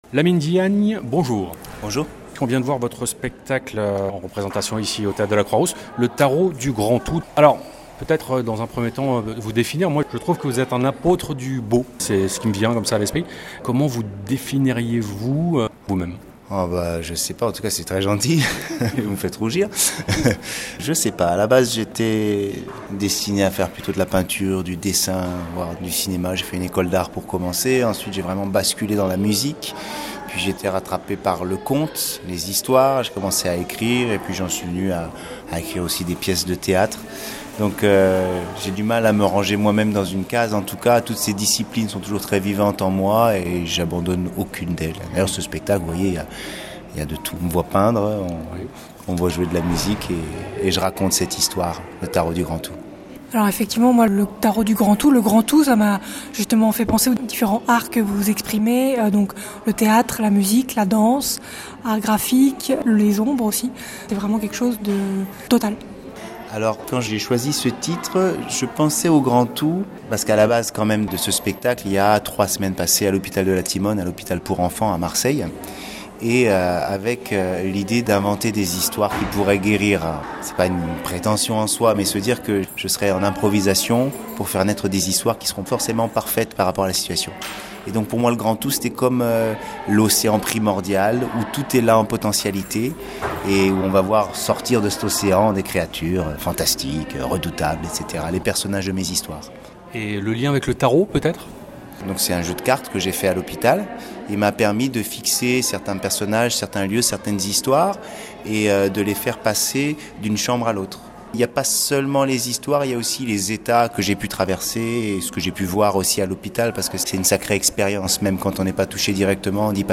Entretien de 8 minutes